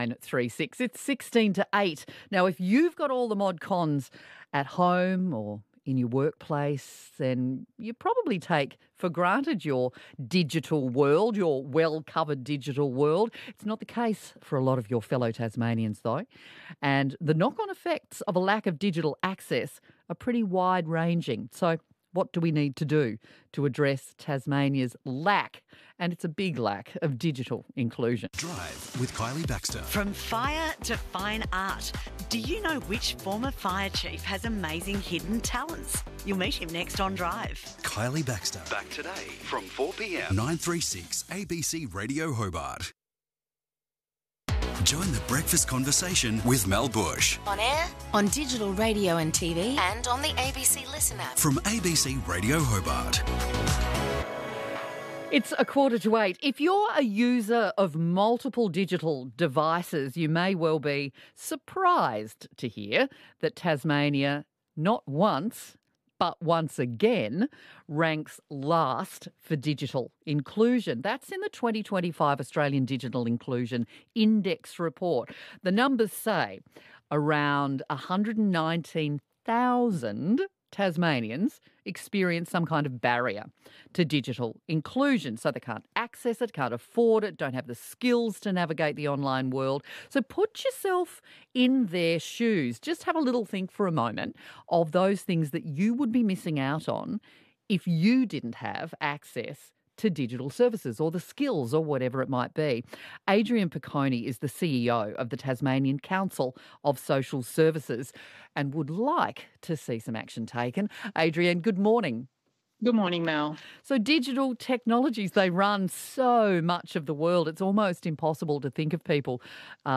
Media: Interview